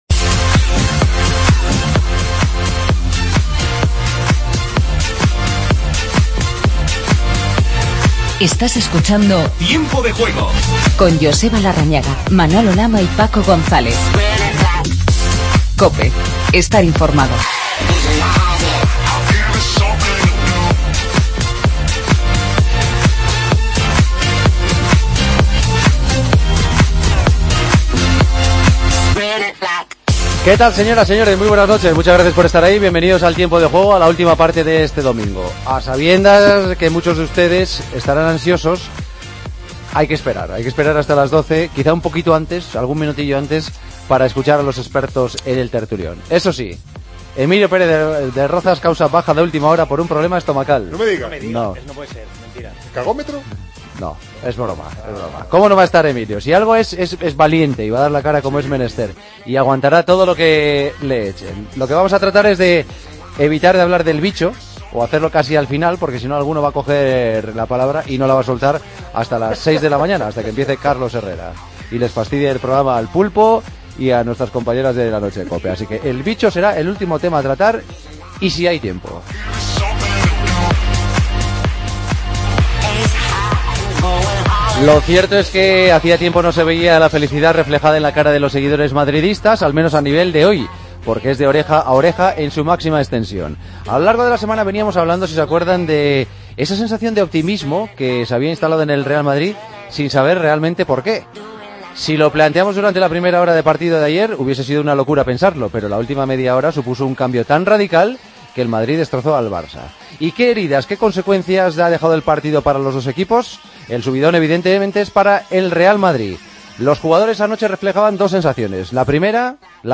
Tras la victoria del Real Madrid en el Camp Nou, la Real Sociedad ganó en Sevilla. Entrevista a Zurutuza y escuchamos a Vitolo. Otros resultados de la 31ª jornada de Liga.